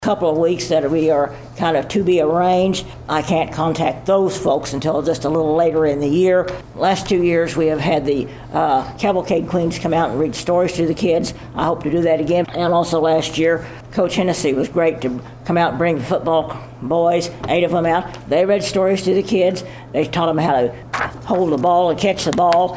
Library Report Clip 1.mp3